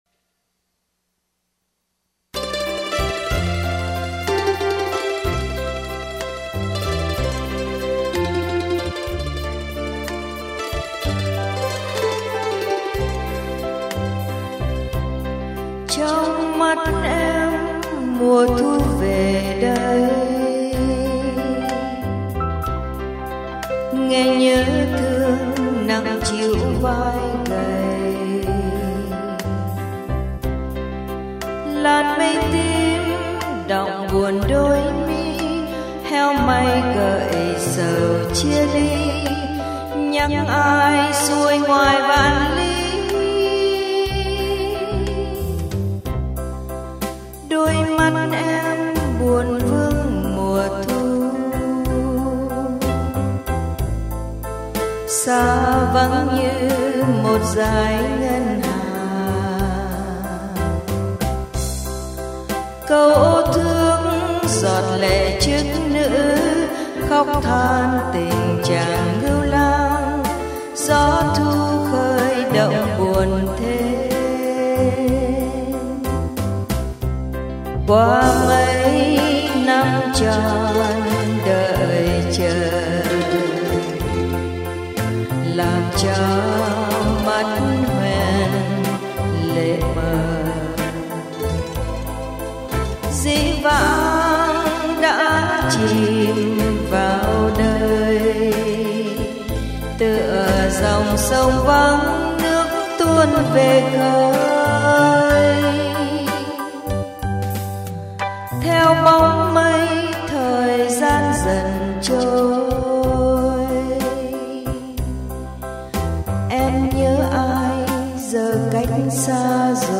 Nhạc đệm